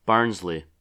Barnsley (/ˈbɑːrnzli/
En-us-Barnsley.oga.mp3